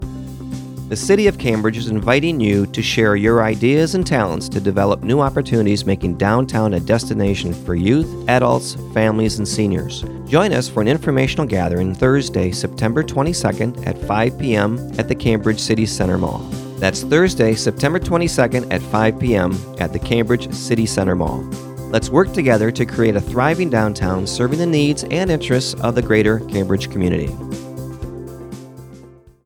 • Radio Public Service Announcements (